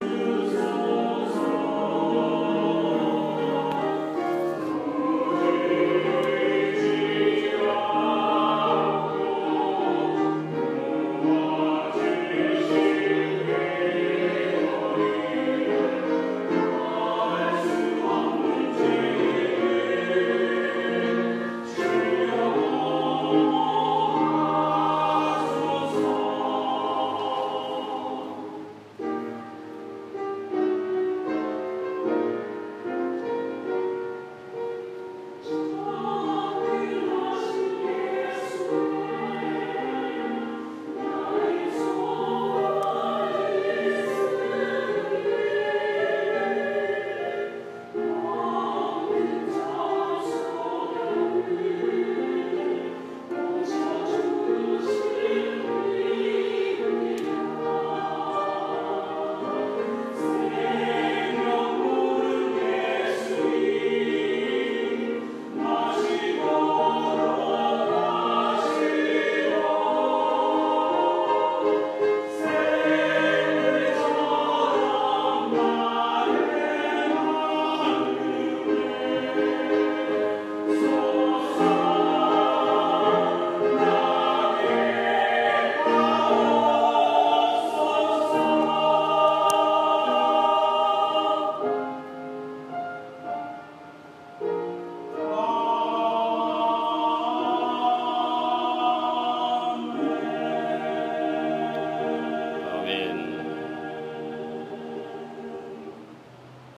8월 14일 주일 찬양대 찬양(내 영혼의 구주)